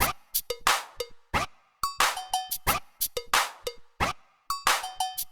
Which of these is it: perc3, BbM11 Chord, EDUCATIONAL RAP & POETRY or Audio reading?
perc3